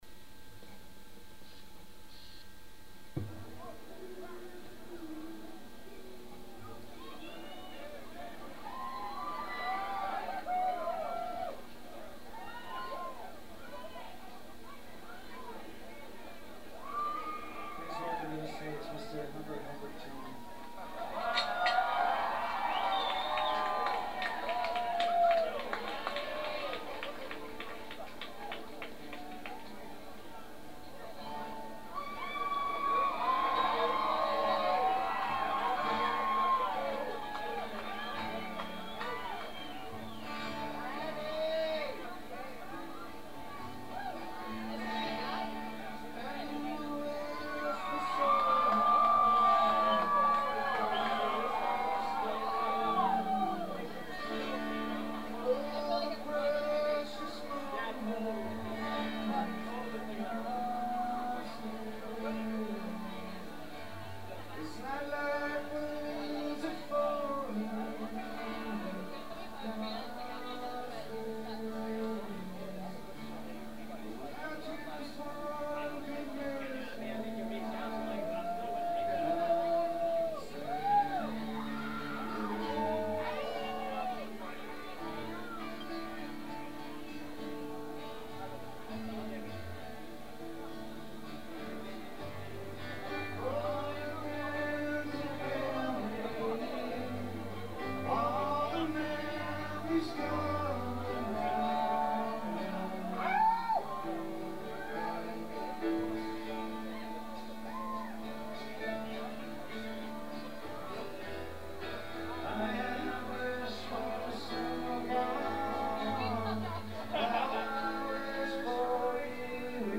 carrying an acoustic guitar.